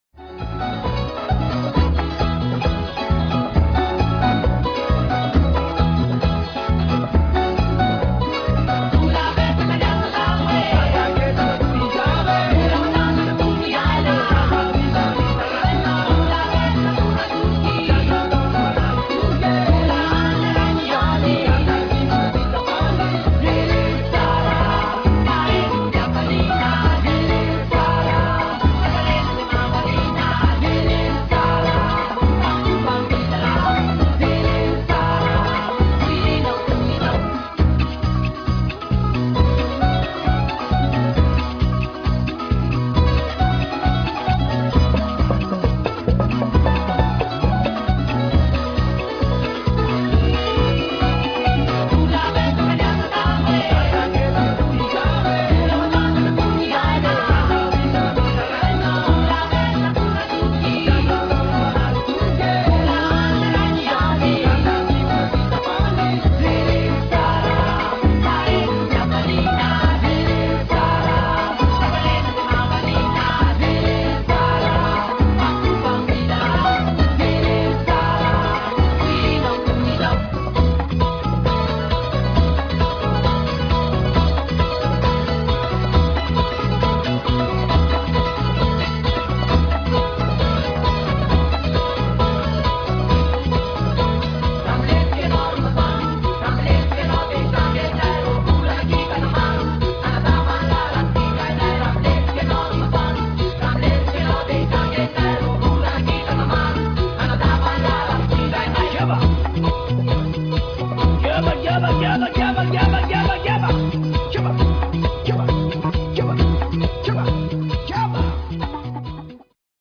that funky group from the island of Madagascar sing